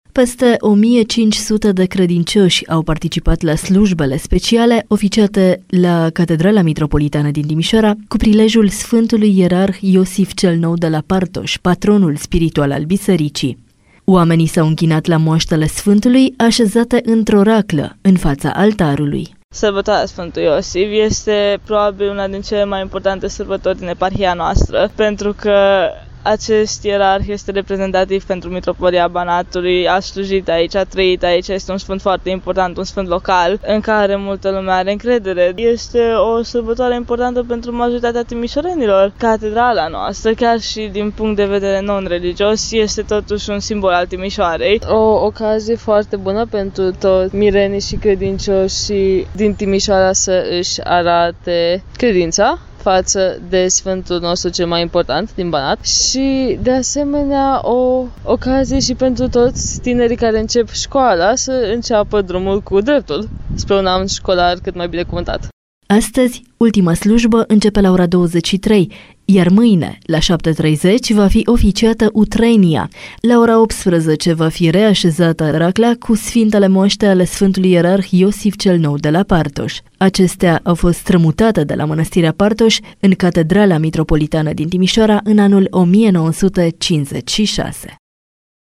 Sute de oameni, la hramul Catedralei Mitropolitane din Timișoara